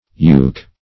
yuke - definition of yuke - synonyms, pronunciation, spelling from Free Dictionary Search Result for " yuke" : The Collaborative International Dictionary of English v.0.48: Yuke \Yuke\ ([=u]k), v. i. & t. Same as Yuck .
yuke.mp3